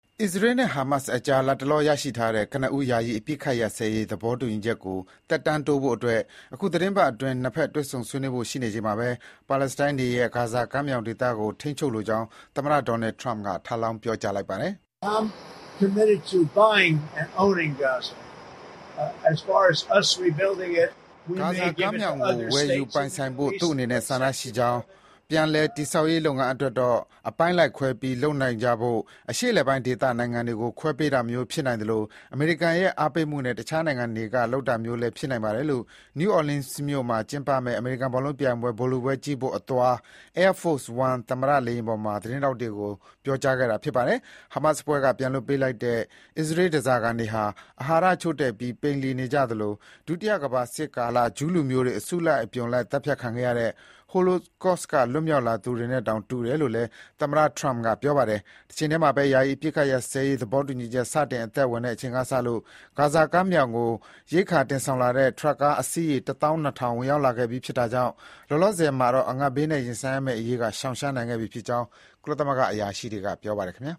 နယူးအော်လင်းမြို့ကိုအသွား လေယာဥ်ပေါ်မှာ သတင်းထောက်တွေနဲ့တွေ့ဆုံစဥ်။ (ဖေဖော်ဝါရီ ၉၊ ၂၀၂၅)